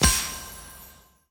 Special & Powerup (6).wav